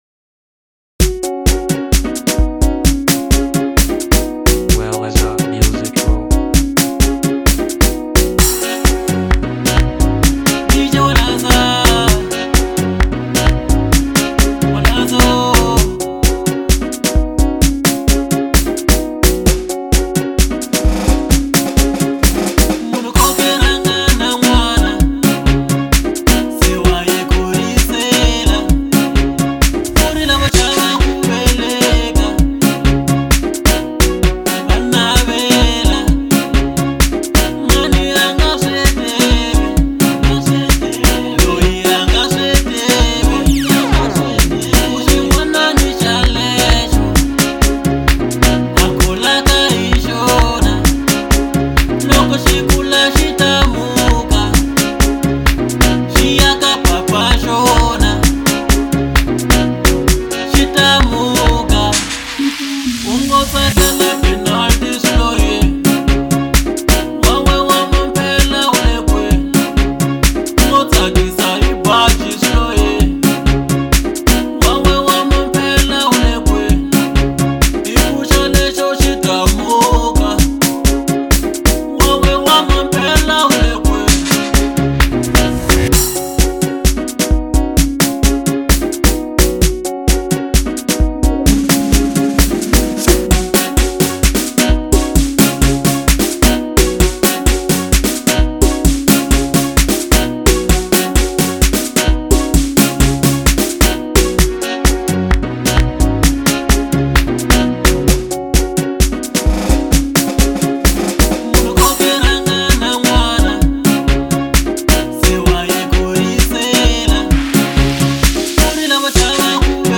03:44 Genre : Local House Size